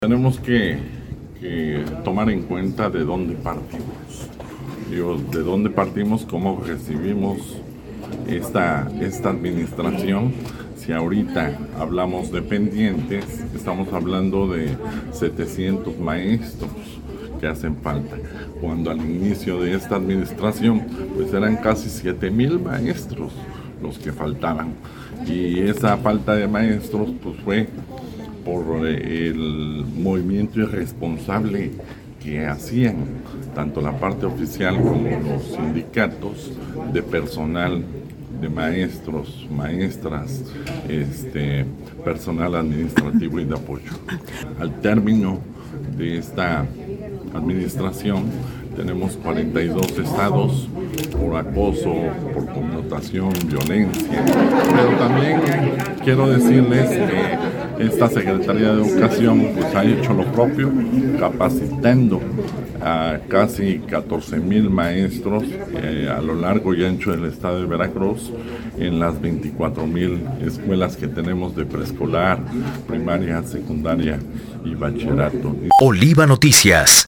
Entrevistado durante su visita a Orizaba, expresó que el sexenio del gobernador Cuitláhuac García buscó generar infraestructura que beneficiara a los estudiantes, pero que aún existen temas pendientes como lo es la ausencia de profesores.